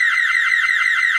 fireAlarmReverbLoop.ogg